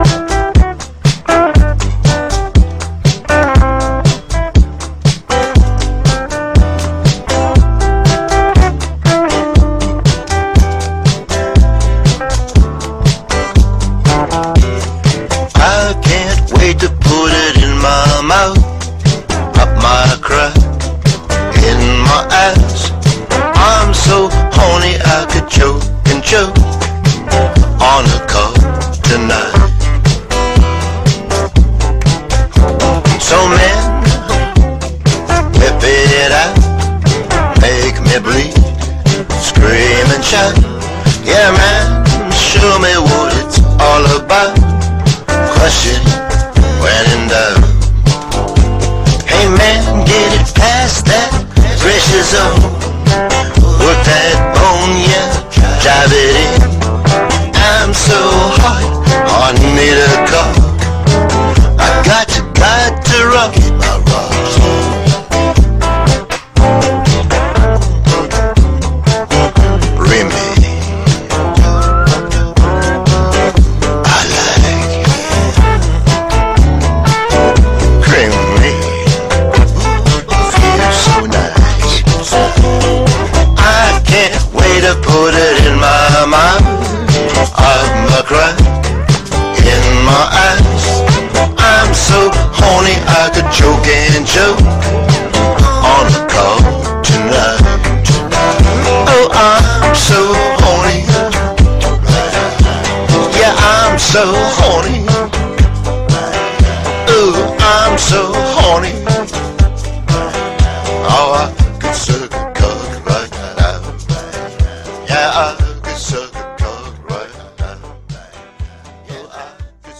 BPM120
Audio QualityPerfect (Low Quality)